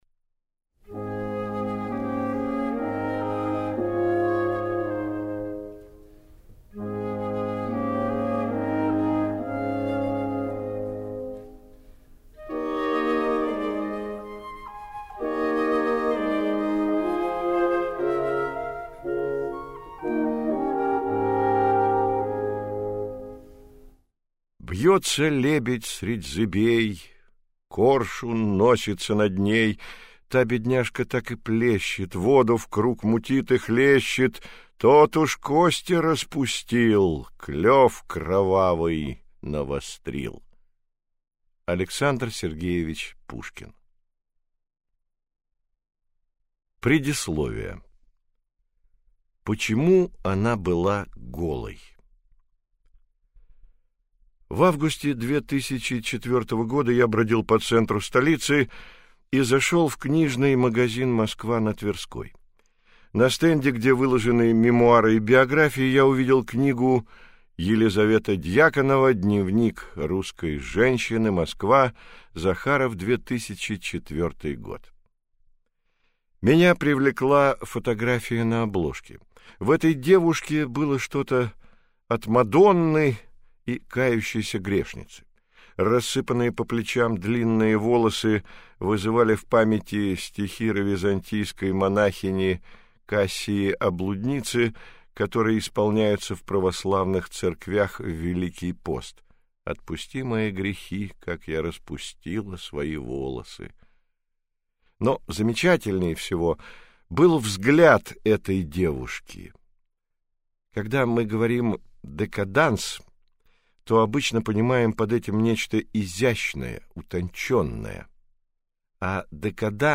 Аудиокнига Посмотрите на меня. Тайная история Лизы Дьяконовой | Библиотека аудиокниг